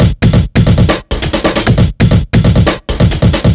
Spitfire Break 135.wav